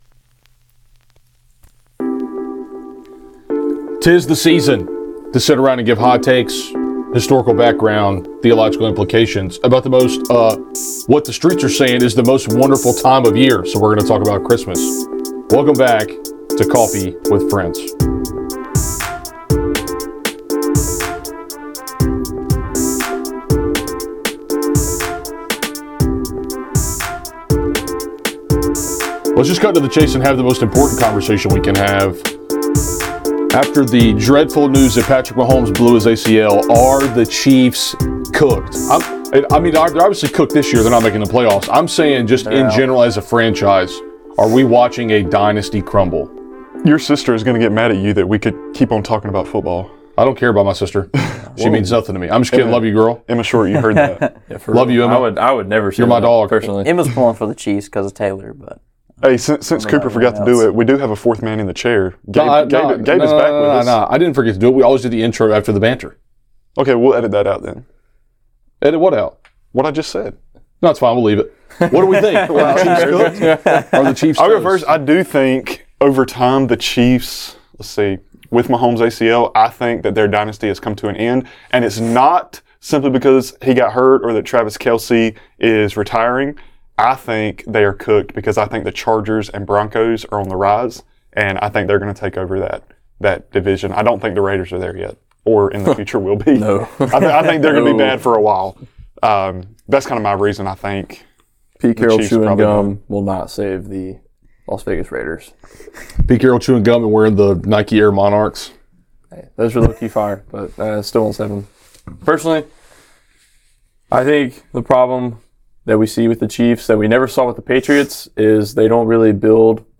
Specifically, we get into the traditions and implications of the Christmas Holiday. But that all comes after a debate over the future of the Chiefs and the Panthers playoff hopes. Also, for about 8 minutes in the middle, the microphone is picking up someone’s heart beat, so don’t freak out when you hear it.